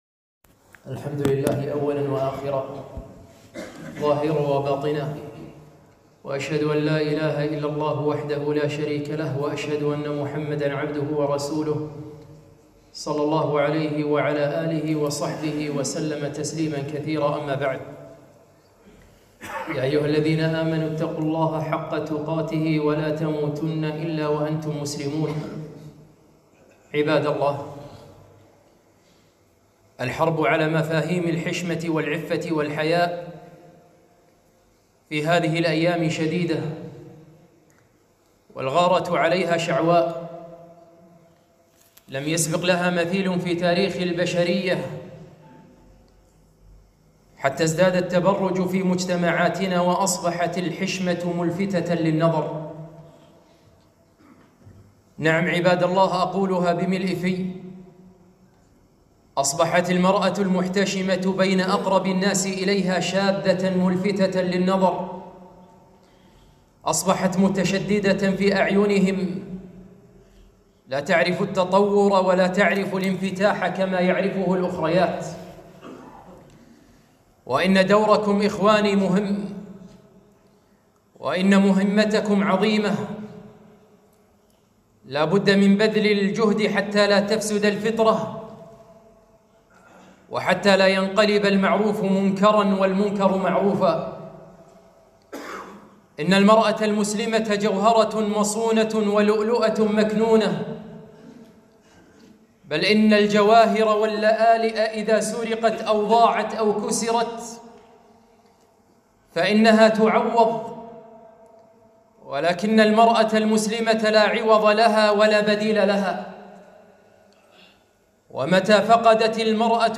رسالة إلى اللؤلؤة المكنونة - خطبة